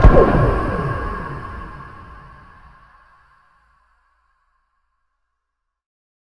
Teleport.mp3